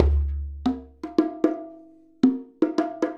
Djembe and Bongos 02.wav